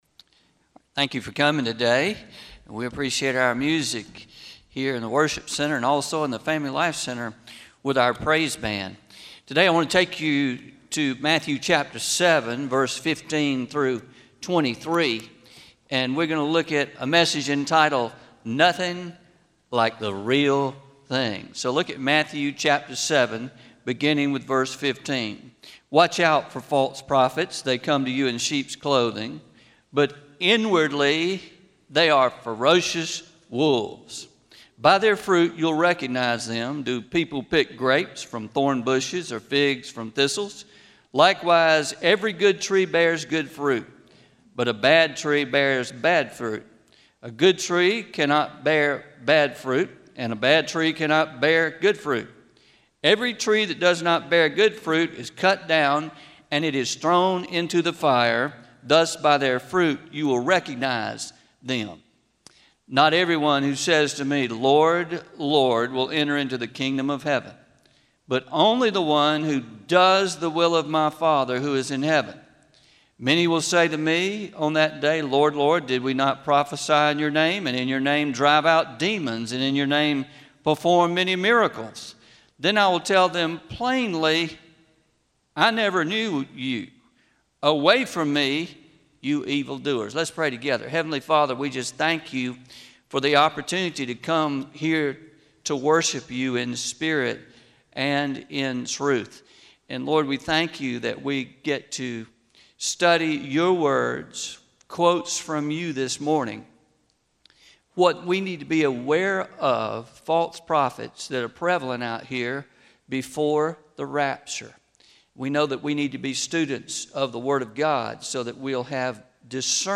10-11-20a – Sermon – Nothing like the Real Thing – Traditional